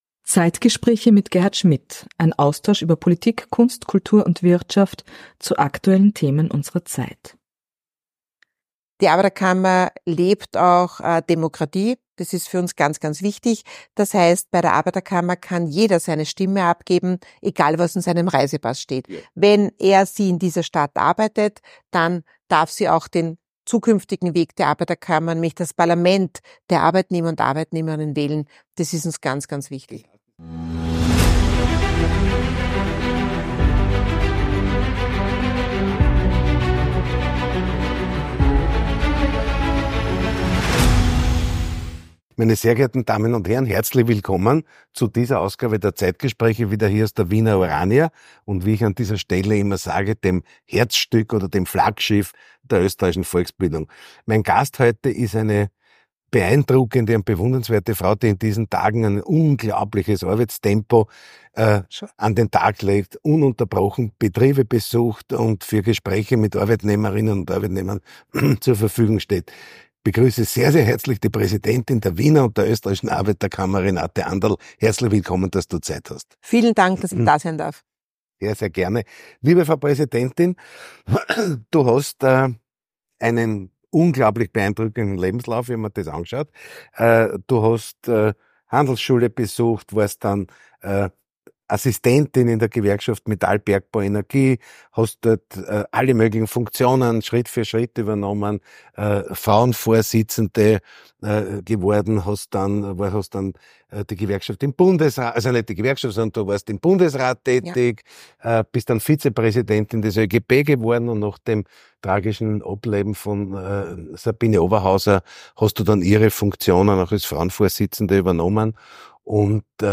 Die Präsidentin der Arbeiterkammer, Renate Anderl, betont in einem ausführlichen Interview die Bedeutung von Demokratie in der Arbeiterkammer.